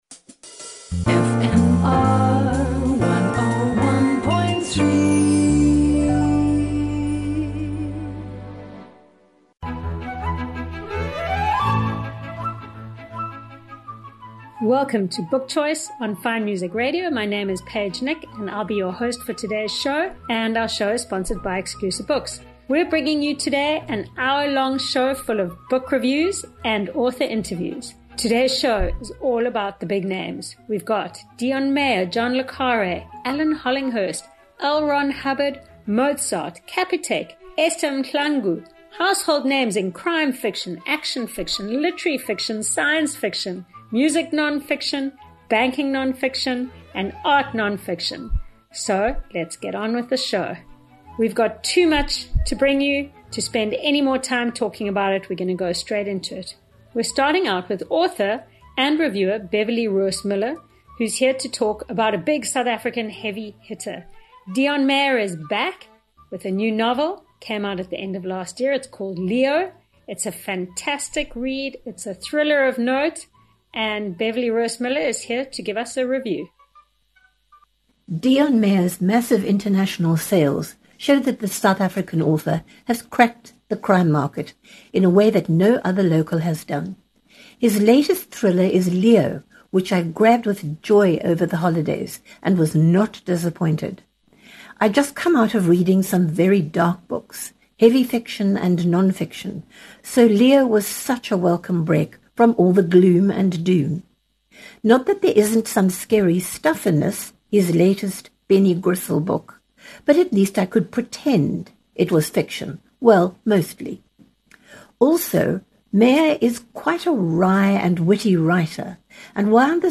Cape Town’s top book reviewers will entertain and inform you as they cheerfully chat about the newest and nicest fiction and non-fiction on current book shelves. You love author interviews?